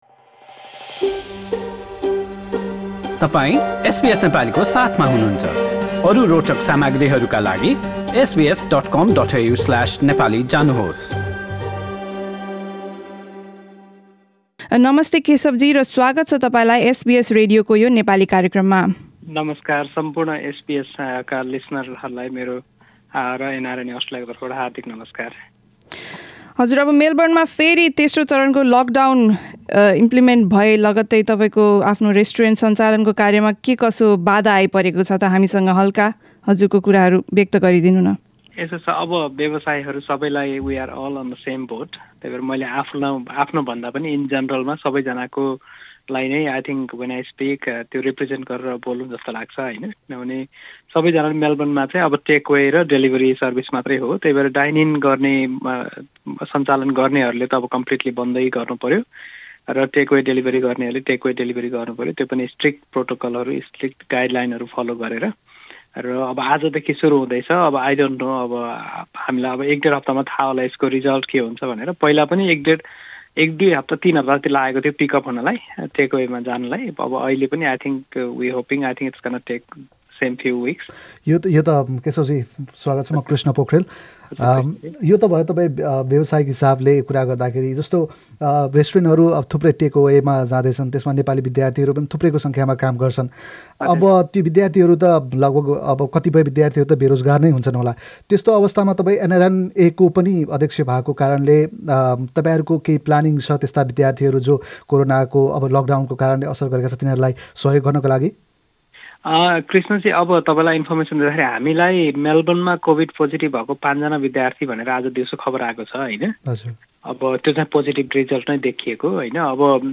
हामीले गरेको कुराकानी